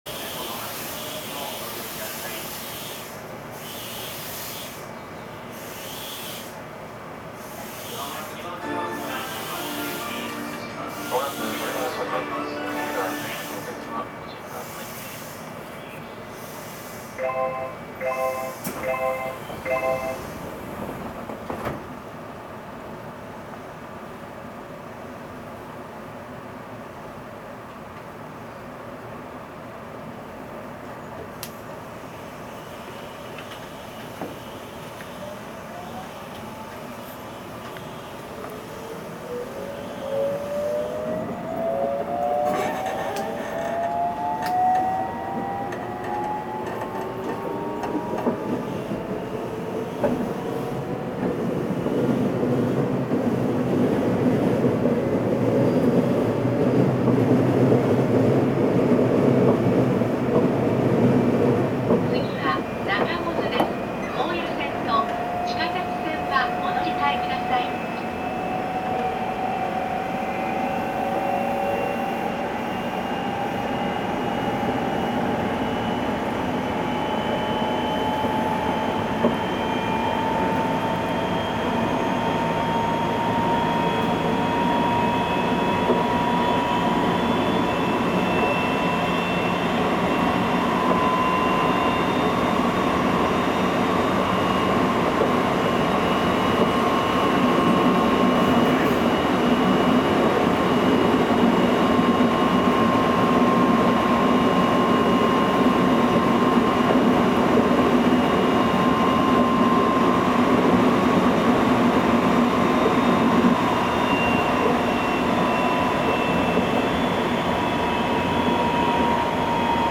走行音
7000系 録音区間：深井～中百舌鳥(お持ち帰り)